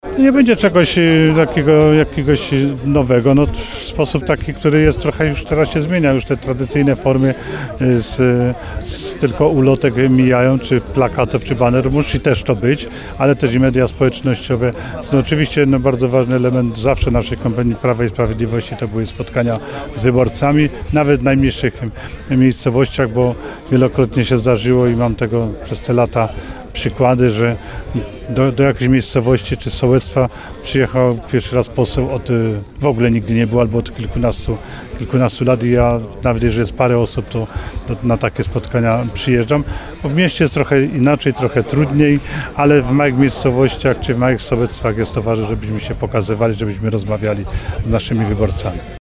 Dużo zależy od każdego kandydata, który musi też włożyć duży wysiłek, żeby odpowiedni wyniki zdobyć – mówił tuż po prezentacji kandydatów wiceminister Stanisław Szwed ("jedynka" na liście wyborczej PiS).